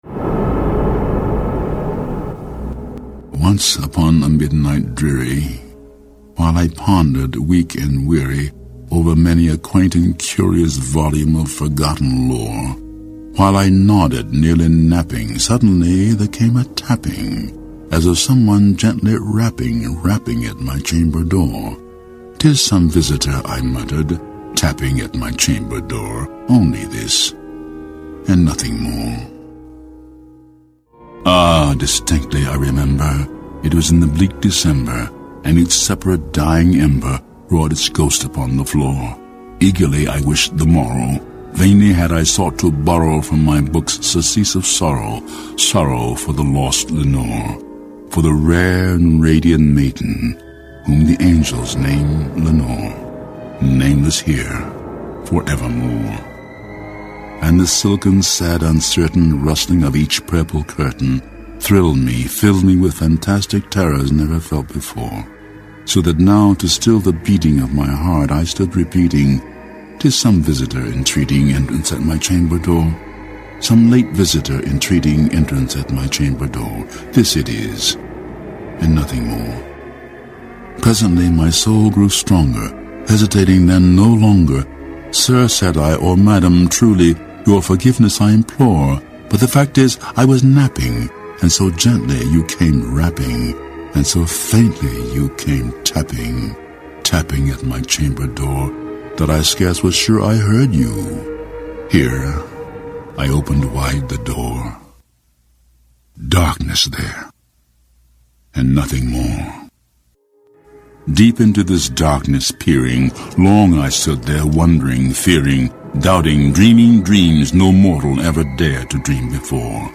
read by James Earl Jones and set to Mozart's "Requiem"